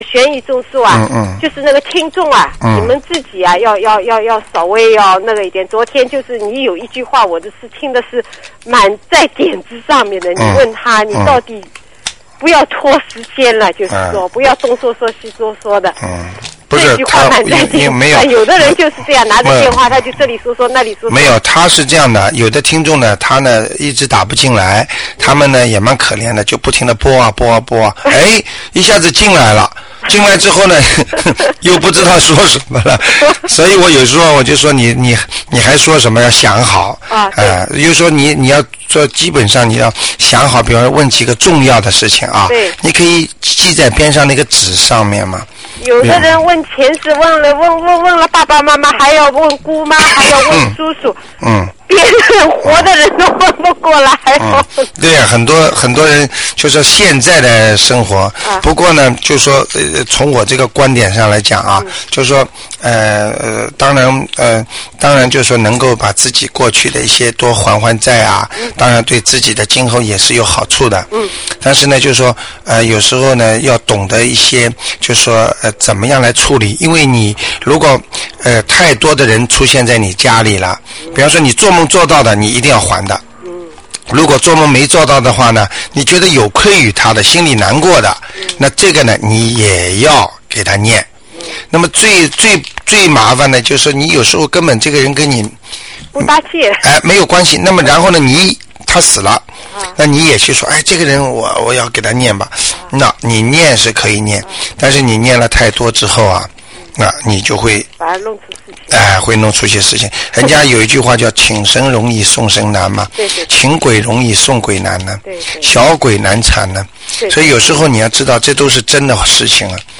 节目录音文字：玄艺问答+综述+直话直说